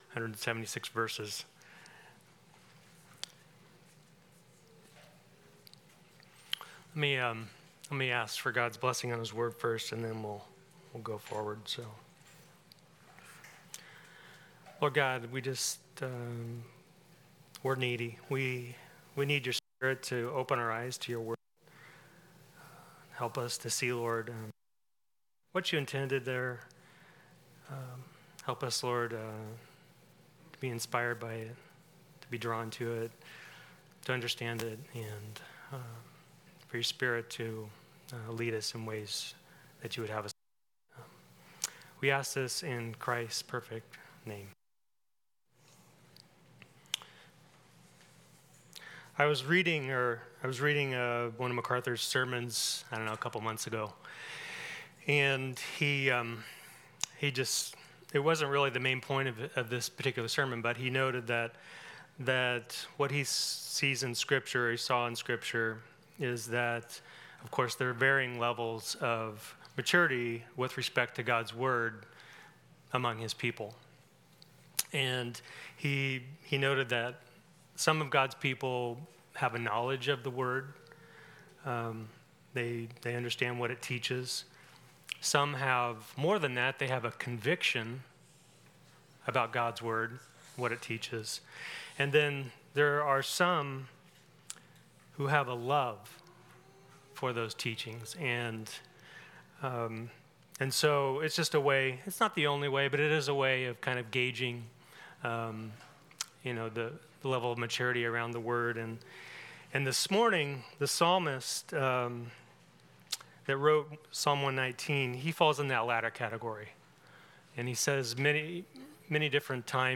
Passage: Psalm 119:1-72 Service Type: Sunday School